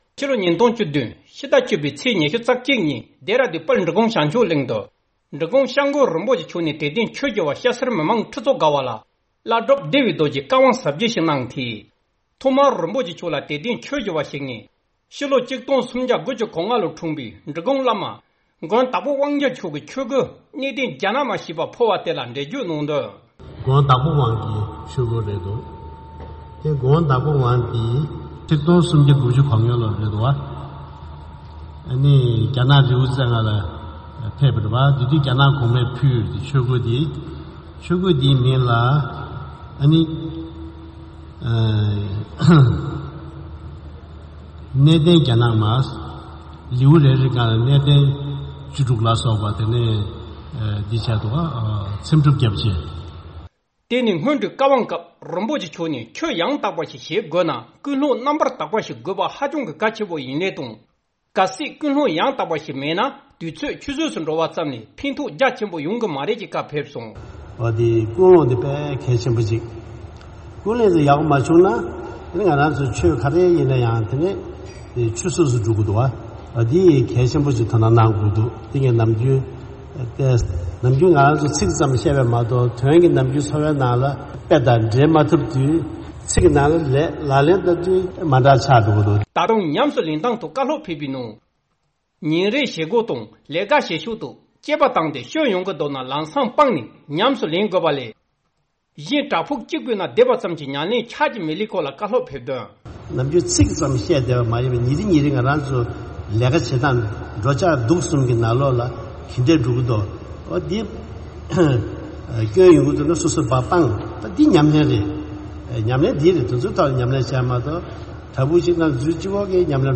འབྲི་གུང་༧སྐྱབས་མགོན་ཆེ་ཚང་རིན་པོ་ཆེ་མཆོག་གིས་བྲག་ཕུག་ནང་བསྡད་པ་ཙམ་གྱིས་ཉམས་ལེན་ཡོང་གི་མ་རེད་ཅེས་གསུངས་བ། ལྡེ་ར་རྡུན་དུ་འབྲི་གུང་༧སྐྱབས་མགོན་ཆེ་ཚང་རིན་པོ་ཆེ་མཆོག་གིས་དད་ལྡན་ཆོས་ཞུ་བ་རྣམས་ལ་གསུང་ཆོས་སྩོལ་བཞིན་པ།
སྒྲ་ལྡན་གསར་འགྱུར། སྒྲ་ཕབ་ལེན།